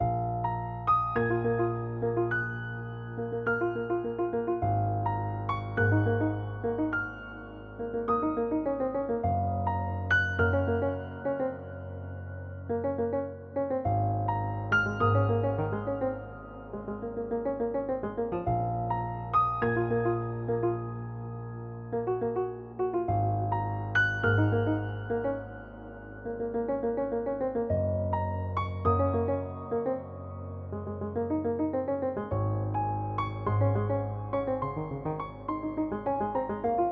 描述：寒冷的钢琴，为LOFI
Tag: 80 bpm Hip Hop Loops Piano Loops 1.01 MB wav Key : C